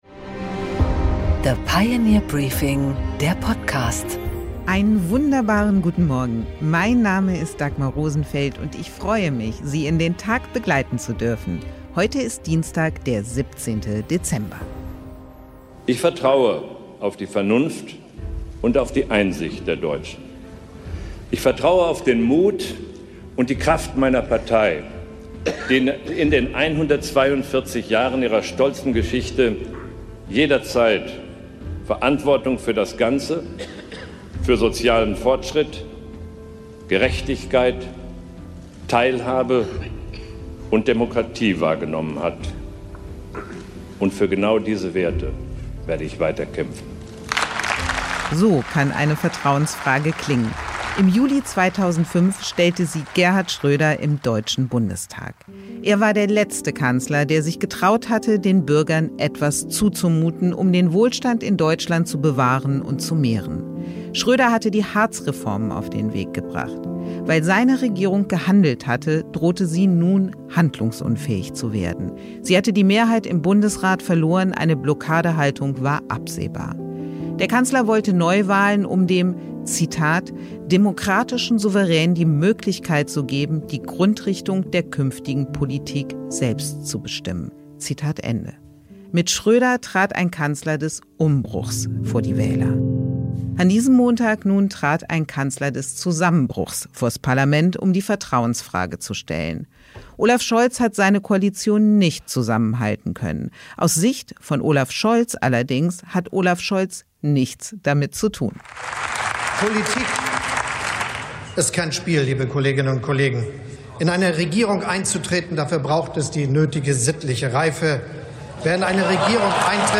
Dagmar Rosenfeld präsentiert das Pioneer Briefing
Im Interview: Prof. Dr. Karl-Rudolf Korte, Direktor der "NRW School of Governance", spricht mit Dagmar Rosenfeld über den anstehenden Wahlkampf und das Scheitern der Ampel.